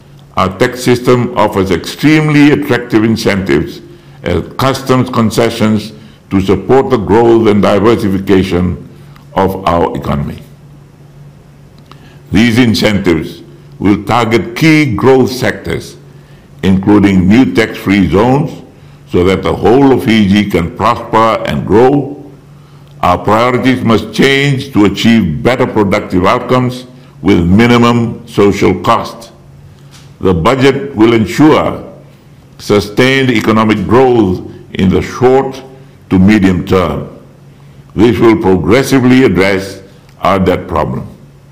While delivering the state of the nation’s address, Rabuka says the 2023-24 national budget will be people-centered and adjustments will be made to ensure sustainable economic growth in the short and medium term.